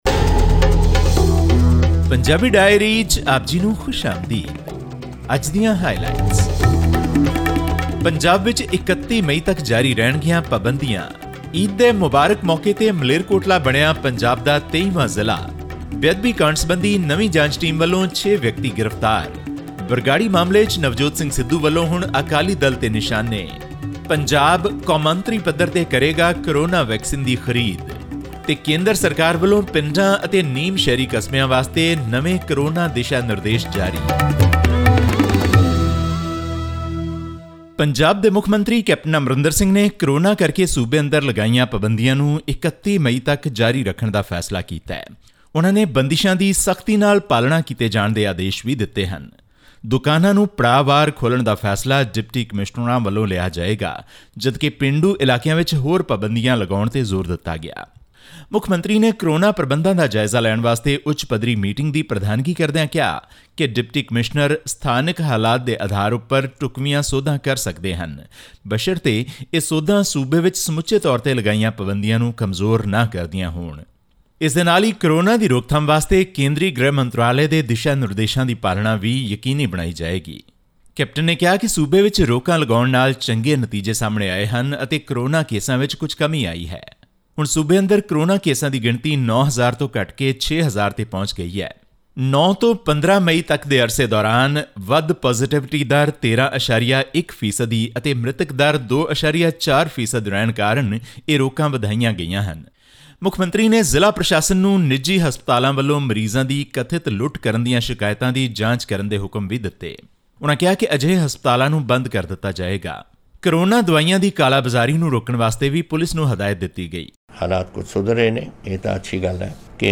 With the state continuing to report high COVID numbers, Punjab Chief Minister Captain Amarinder Singh has ordered an extension of all the existing restrictions up to May 31. Tune into this podcast for a weekly news update from Punjab.
Click on the player at the top of the page to listen to the news bulletin in Punjabi.